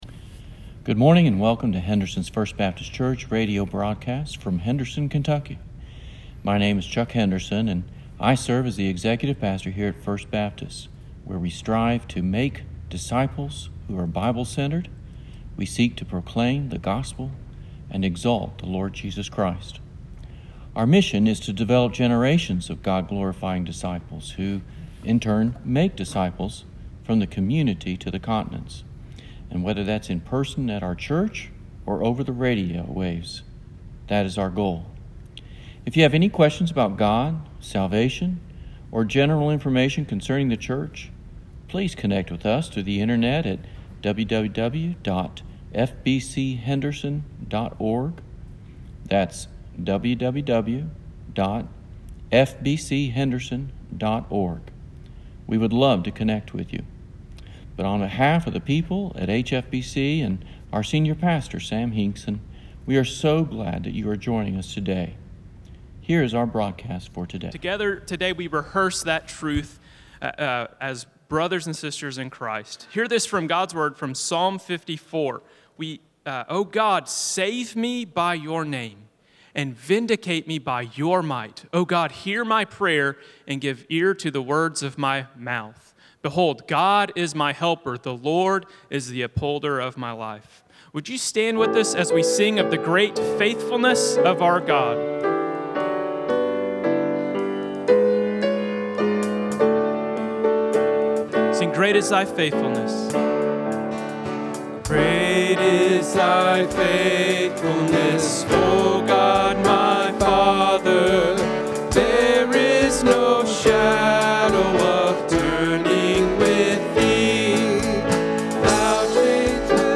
God Tests Us So We Will Learn Sermon